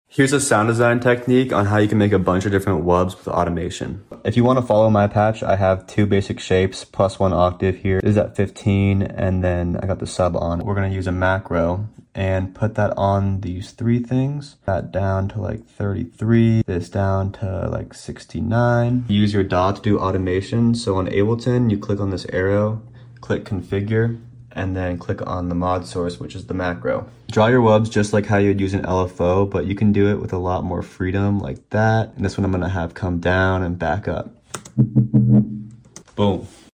SERUM TUTORIAL: HOW TO SOUND DESIGN FREEFORM WUBS WITH AUTOMATION